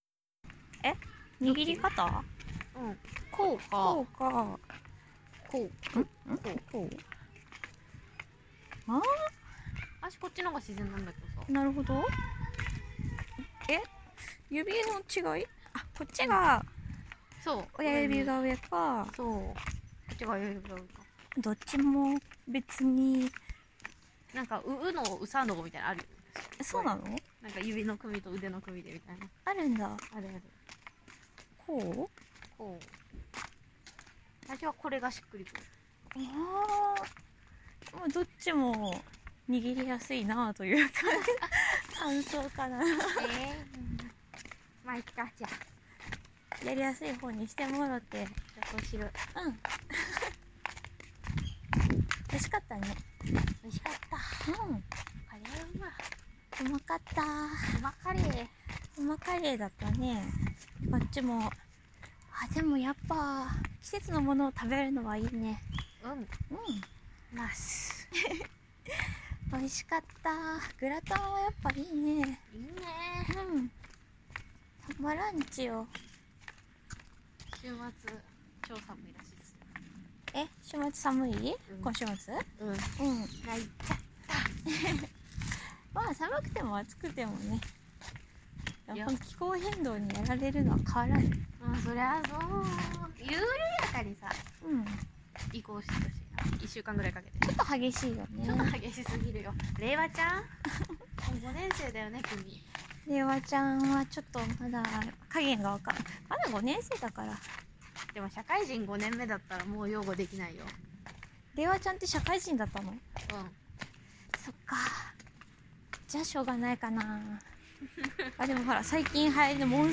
【ガチ実録】同棲同人声優が散歩してるだけ